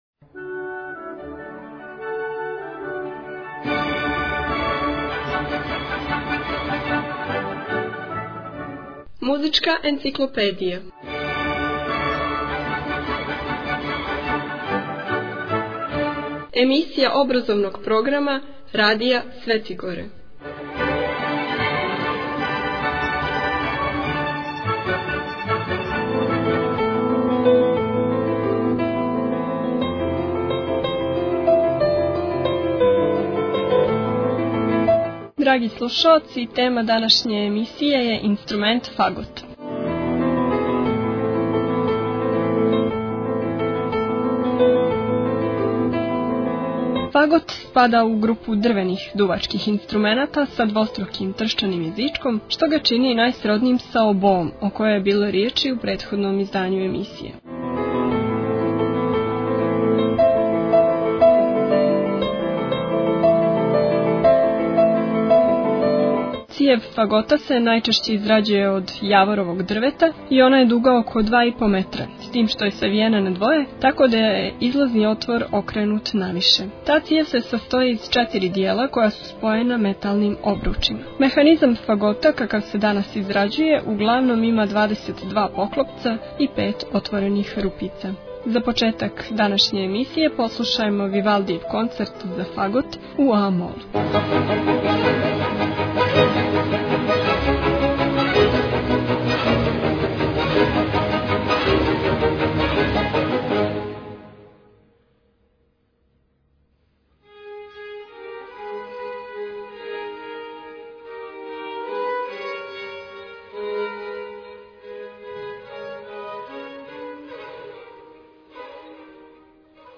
Музичка енциклопедија CLXXXI Tagged: Музичка енциклопедија 42:25 минута (6.08 МБ) У емисији "Музичка енциклопедија" можете чути инструмент фагот и сазнати нешто више о њему.